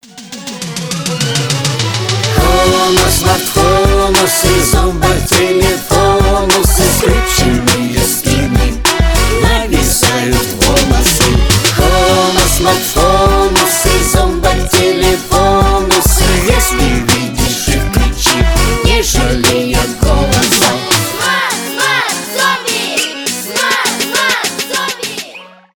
2022 » Русские » Поп Скачать припев